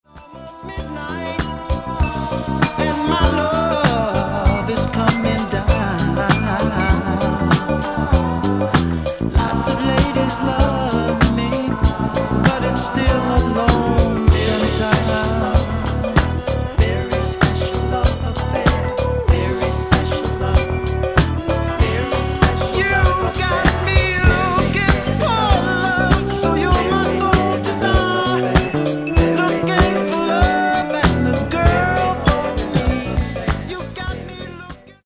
Genere:   Soul | Groove